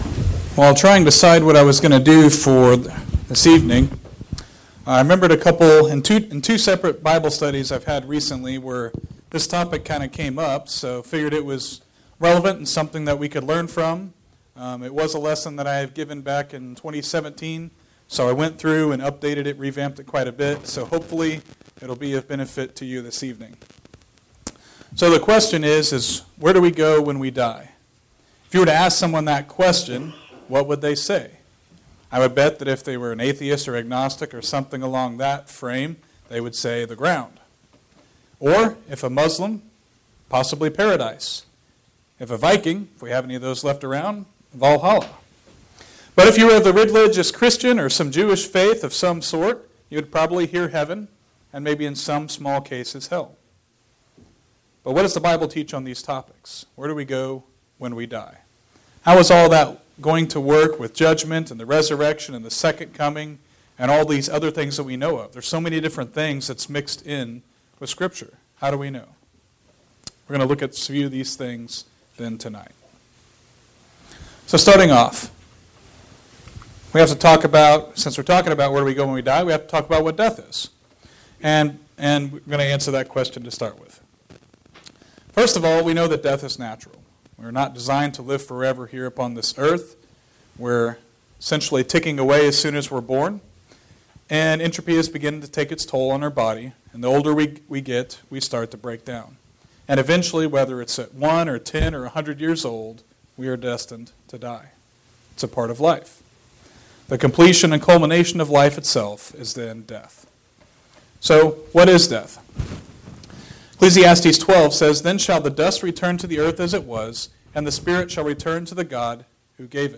Ezek 18:20 Service Type: AM